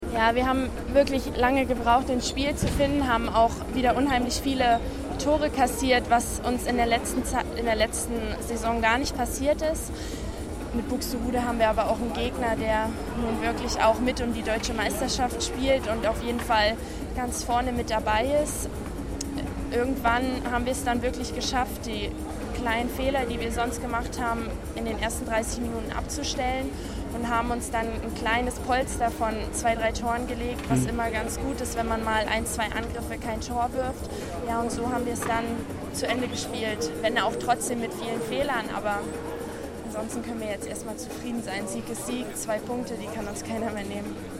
nach dem Abpfiff im L-IZ-Interview